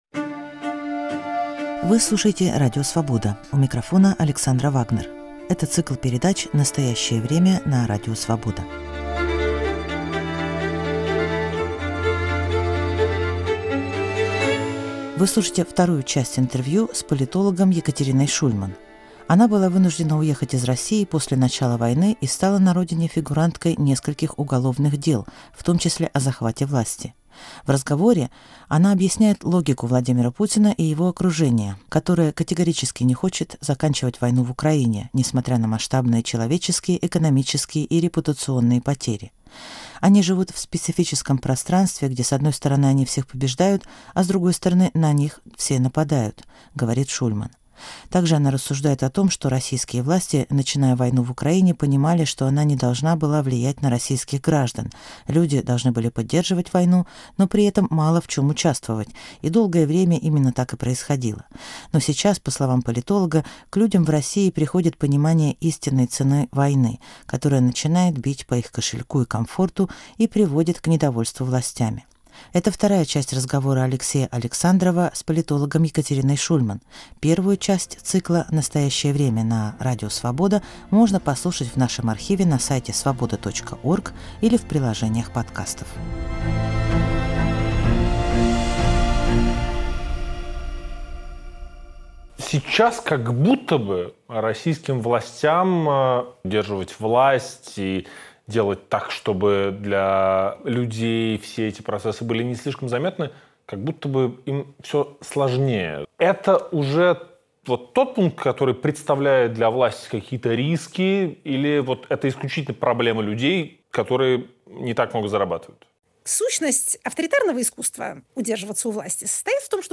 Настоящее Время на Радио Свобода: политолог Екатерина Шульман, часть вторая